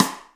VEC3 Percussion 069.wav